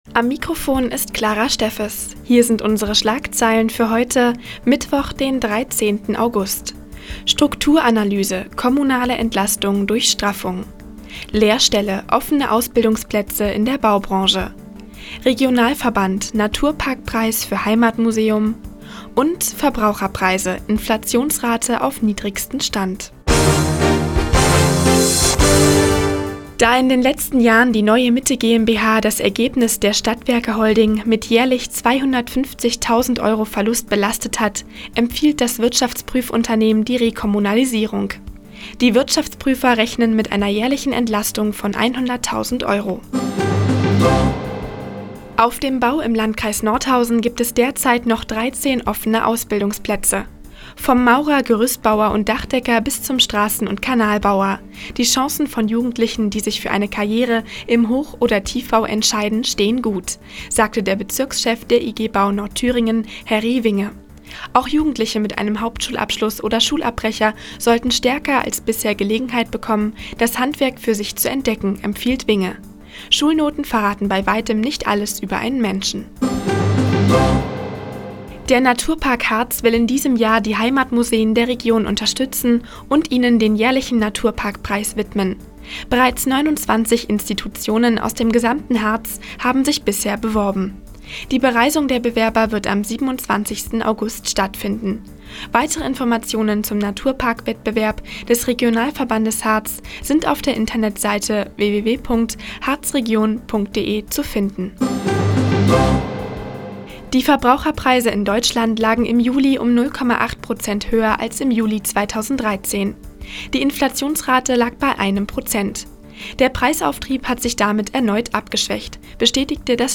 Mi, 15:00 Uhr 13.08.2014 „Der Tag auf die Ohren“ OKN (Foto: OKN) Seit Jahren kooperieren die Nordthüringer Online-Zeitungen und der Offene Kanal Nordhausen. Die tägliche Nachrichtensendung des OKN ist jetzt hier zu hören.